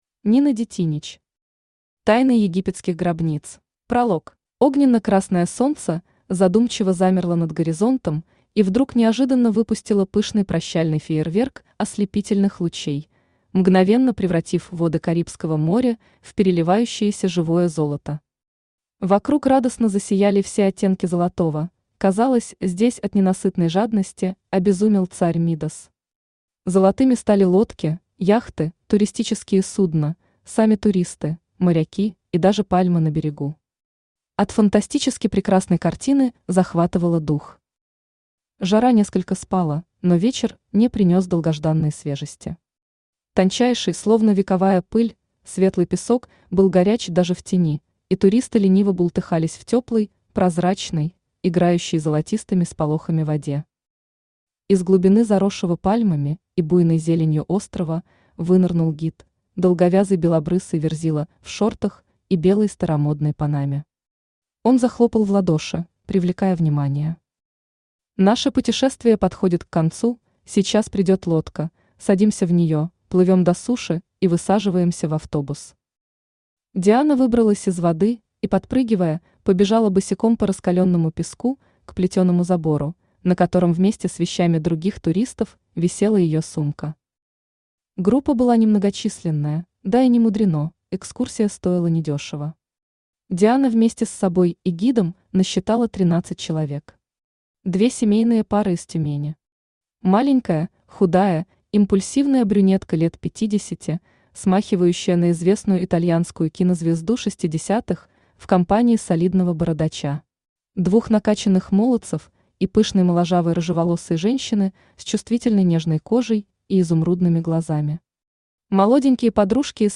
Аудиокнига Тайны египетских гробниц | Библиотека аудиокниг
Aудиокнига Тайны египетских гробниц Автор Нина Дитинич Читает аудиокнигу Авточтец ЛитРес.